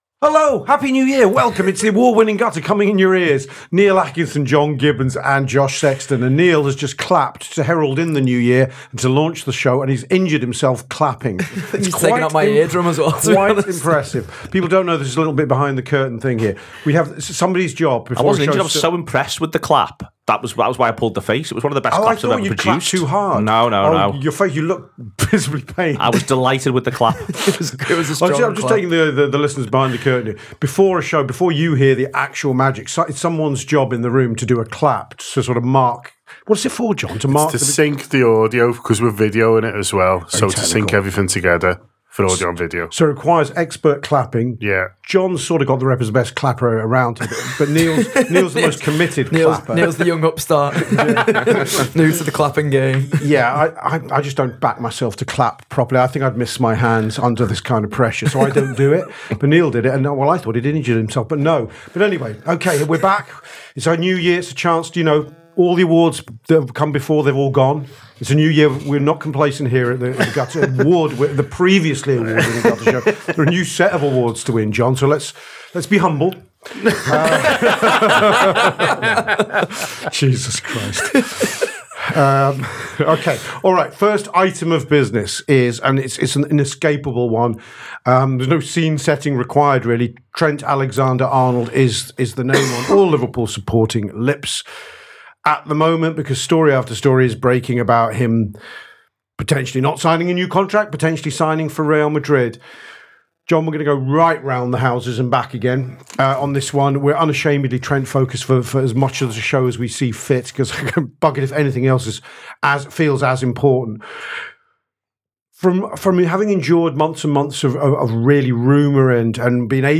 Below is a clip from the show – subscribe for more on Liverpool’s January planning and new contracts..